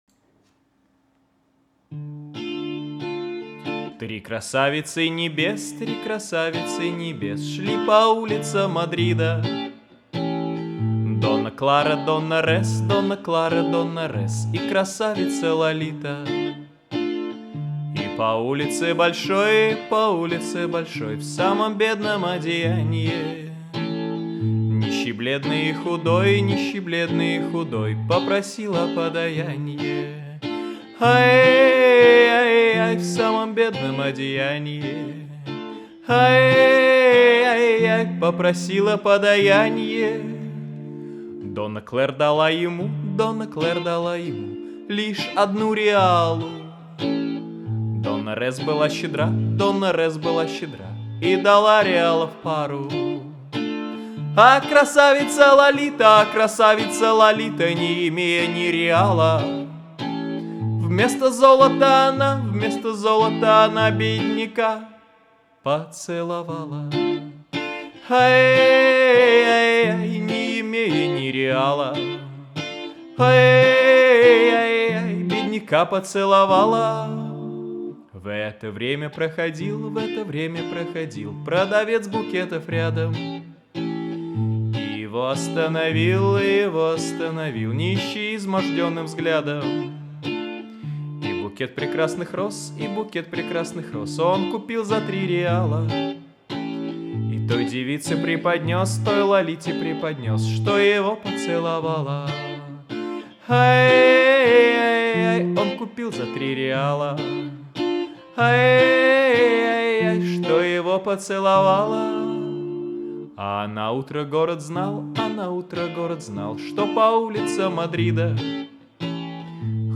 Этакое озорное исполнение, задорное.